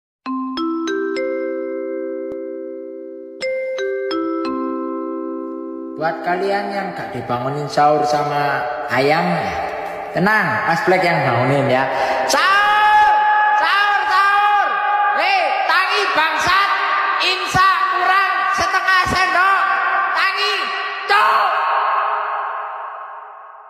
Kategori: Nada dering
Dengan suara khas yang mengingatkan waktu imsak, nada dering ini cocok banget buat kamu yang nggak mau telat sahur.
nada-alarm-sahur-imsak-kurang-setengah-sendok-viral-tiktok-id-www_tiengdong_com.mp3